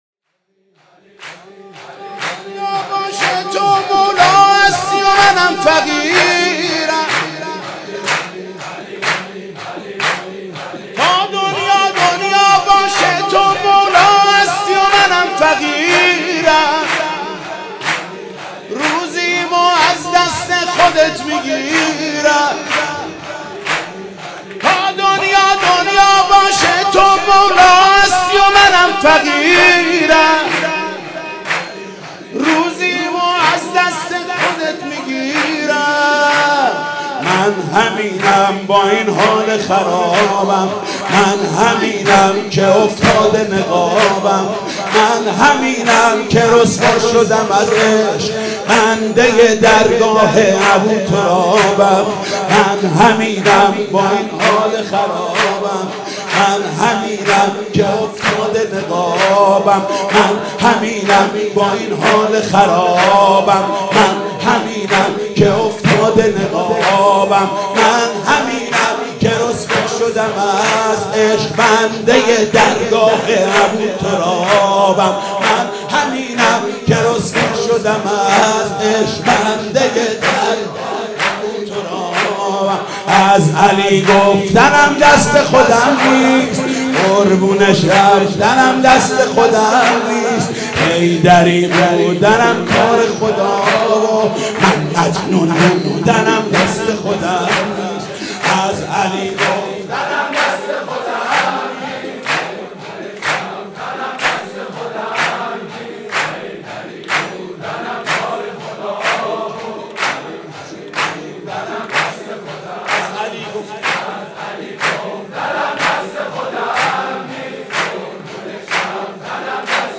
مولودی زیبای